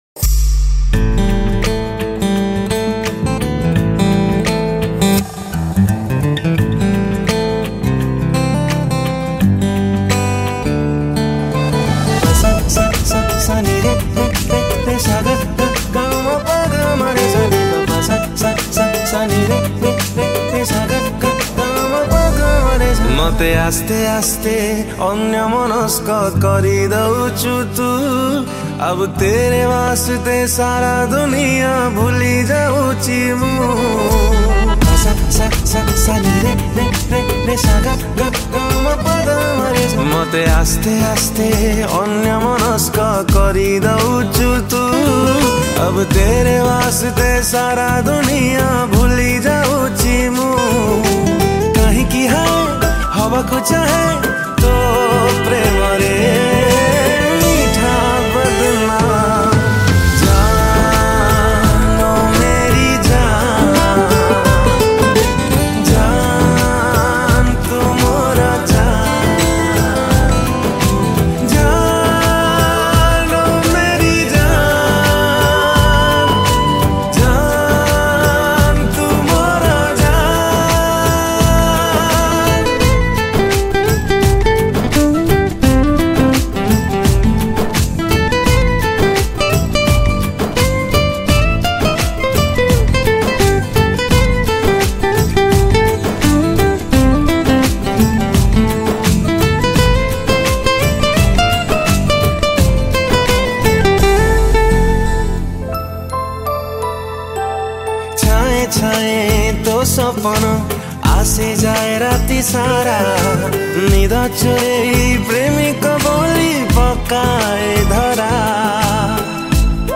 New Odia Romantic Song